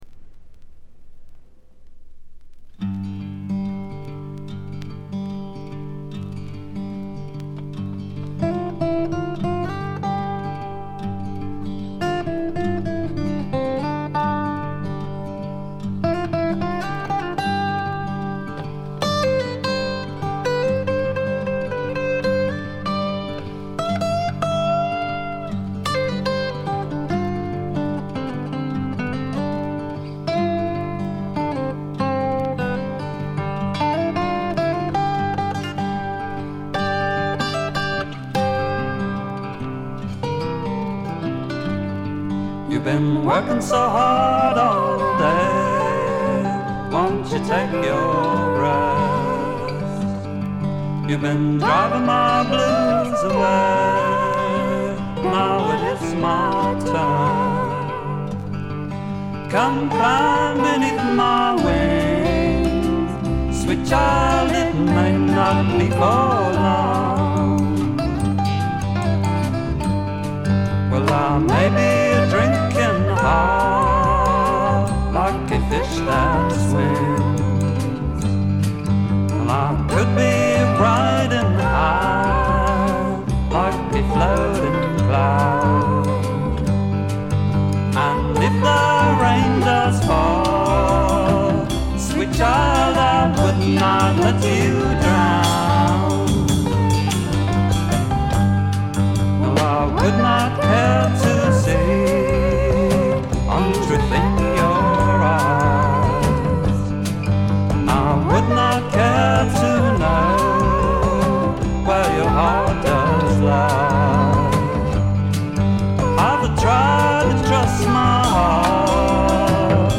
他はB面で一部軽い周回ノイズ、C面D面のスタジオ面は軽微なチリプチ少々で良好。
試聴曲は現品からの取り込み音源です。
Sides 3 and 4, Recorded at IBC Studios,London, August 1968.